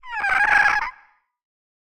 Minecraft Version Minecraft Version 25w18a Latest Release | Latest Snapshot 25w18a / assets / minecraft / sounds / mob / ghastling / ghastling4.ogg Compare With Compare With Latest Release | Latest Snapshot
ghastling4.ogg